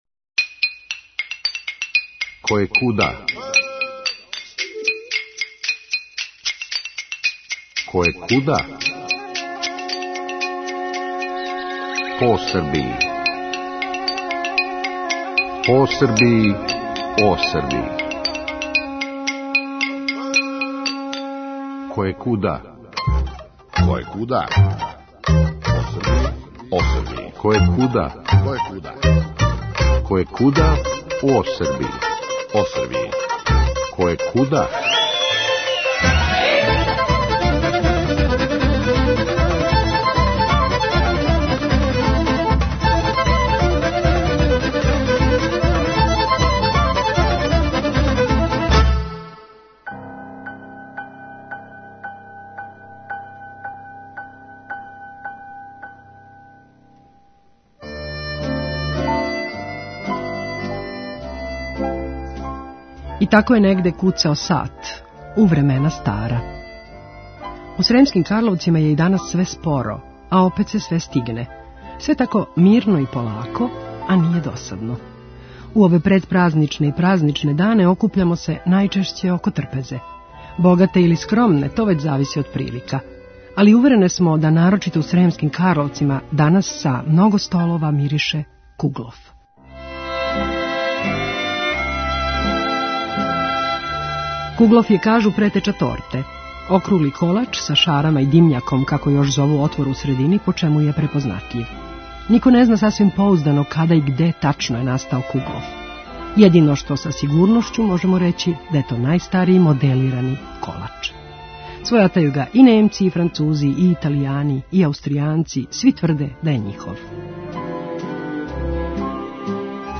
И јесте тако, уверили су нас у то мирни и тихи Карловачки тргови и улице. Прелепа стара здања у центру града, торњеви и звона цркава, понеки пролазник...сунчано поподне - тихо и полако.